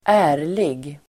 Uttal: [²'ä:r_lig]